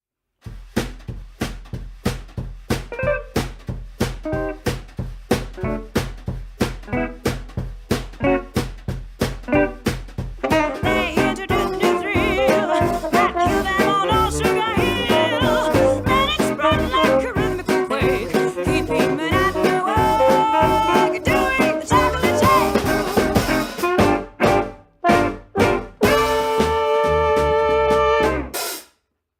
Saxophone ténor-chant
Trombone
Contrebasse
Batterie
Guitare-Chant
Idéal pour la danse et pour cause